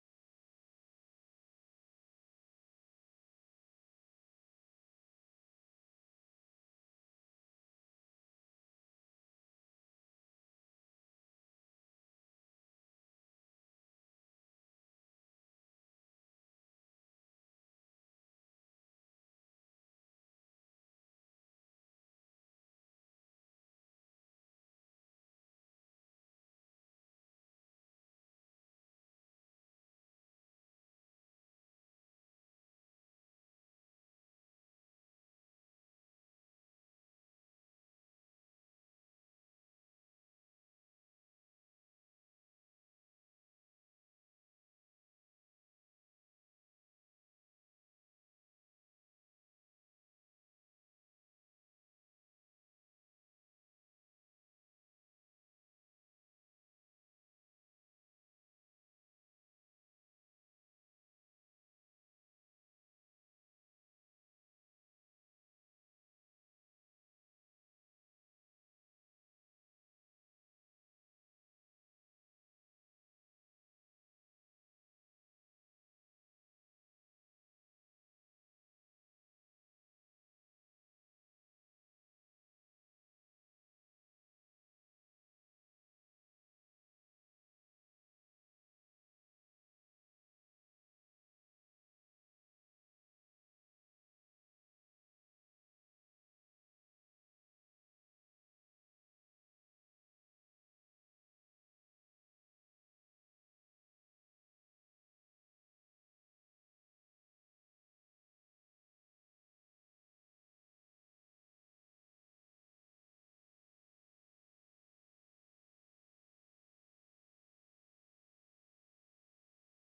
FWC praise and worship on the 24th of November